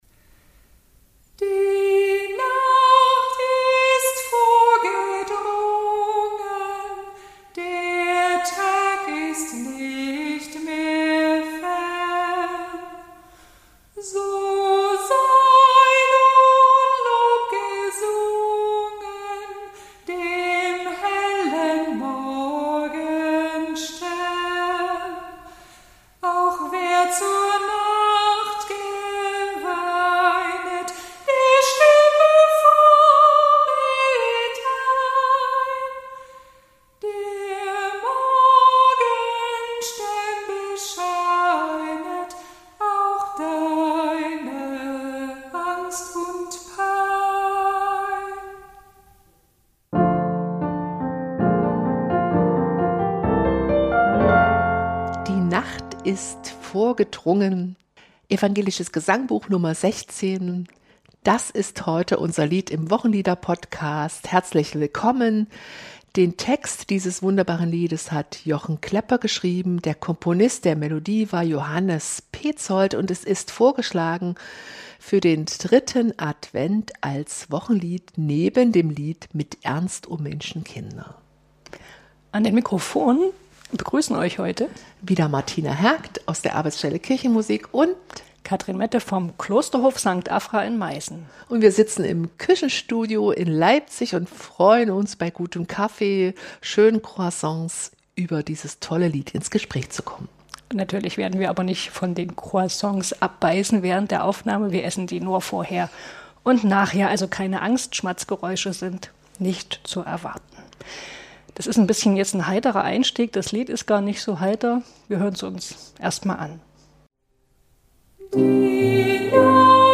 Genug Stoff für 50 Minuten Gespräch.